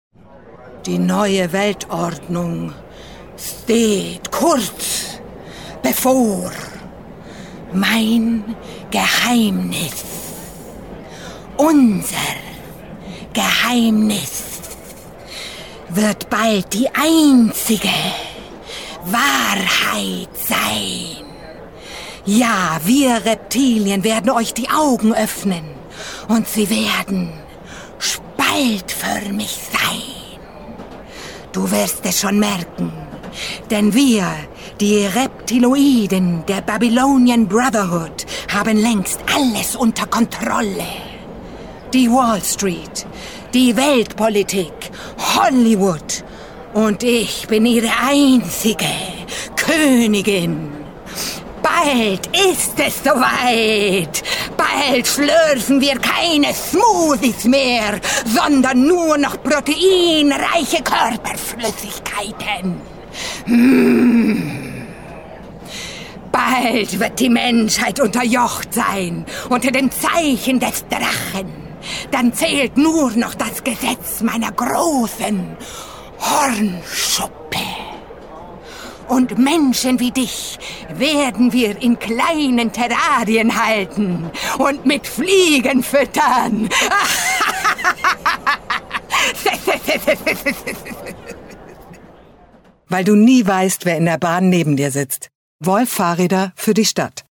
Reptiloidenkoenigin